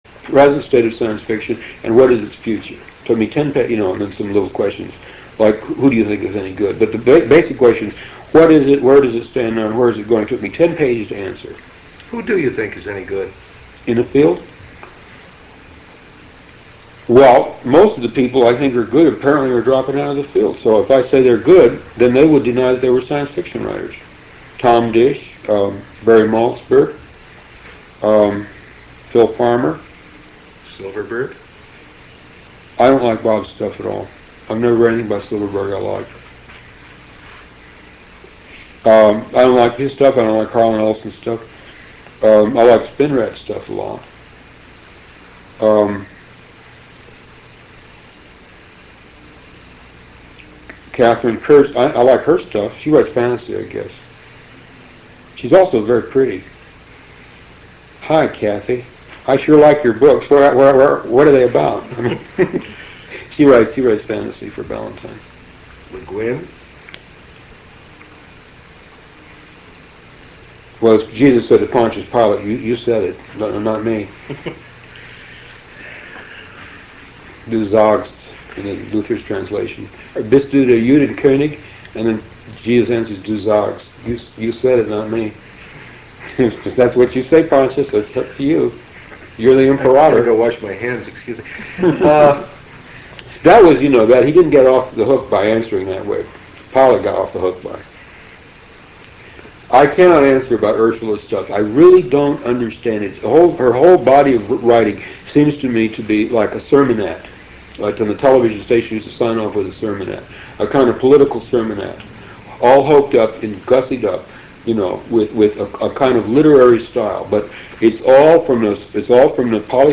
Interview with Philip K Dick 9